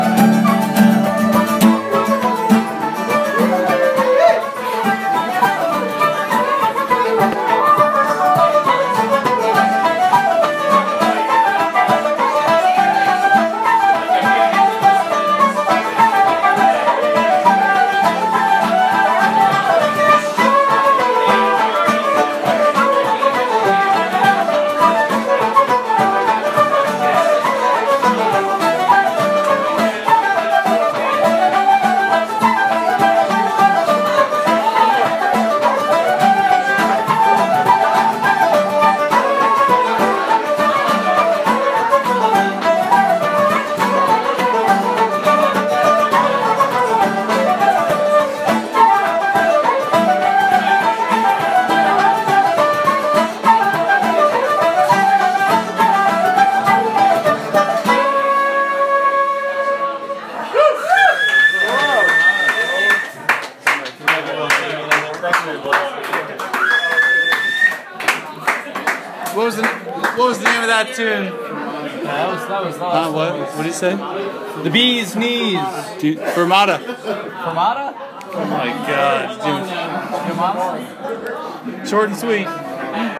[See Video] O'Connell's Snug, Winter 2016; Hot Tune.